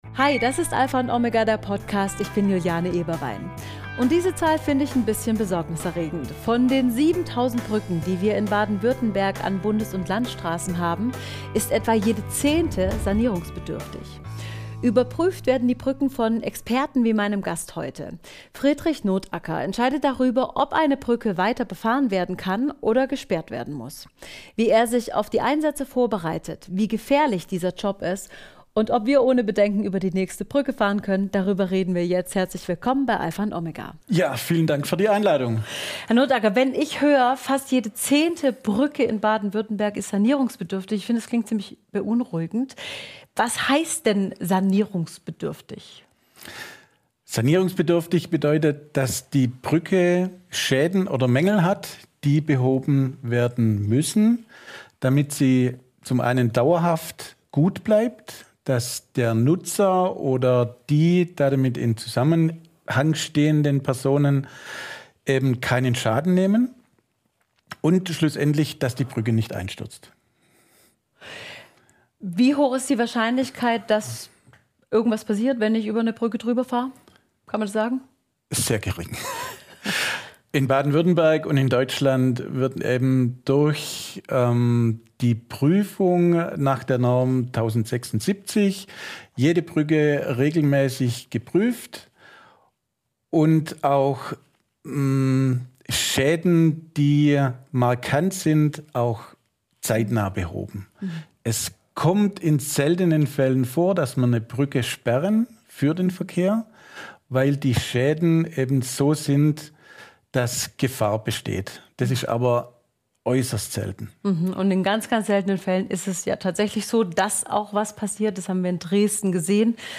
In dieser Folge sprechen wir mit dem Brückenprüfer